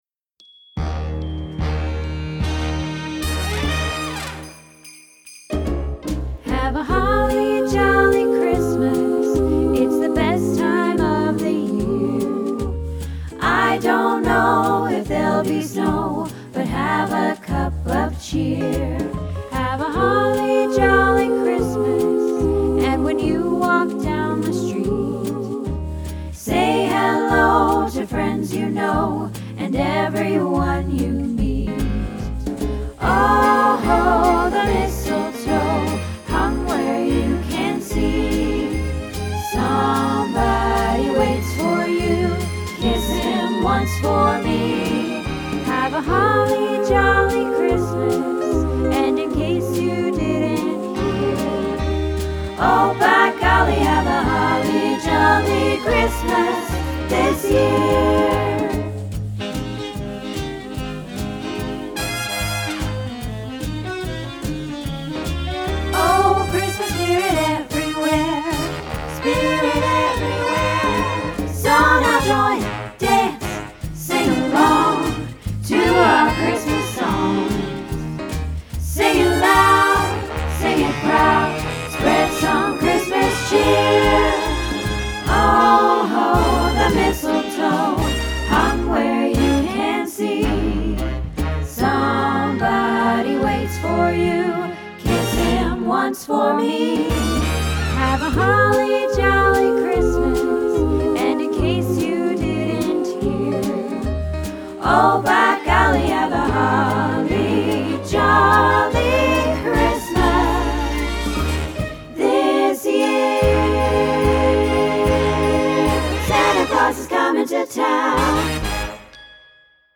Holly Jolly Christmas - Practice